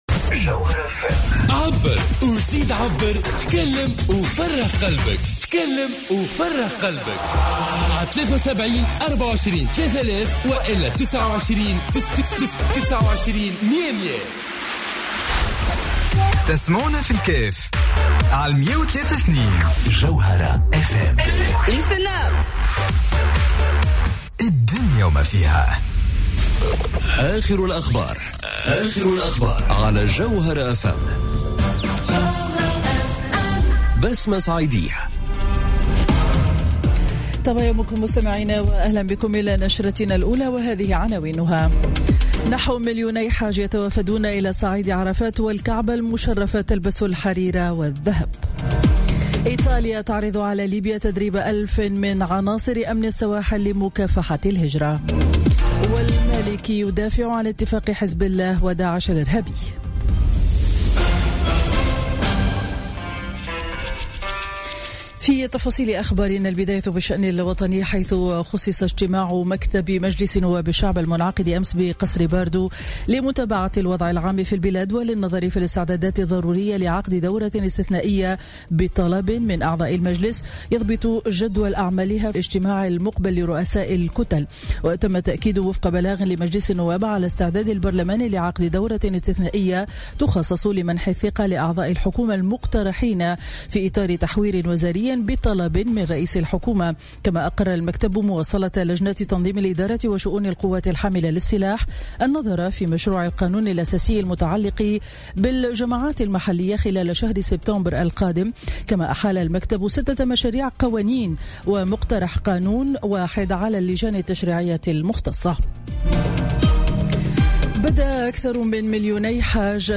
نشرة أخبار السابعة صباحا ليوم الخميس 31 أوت 2017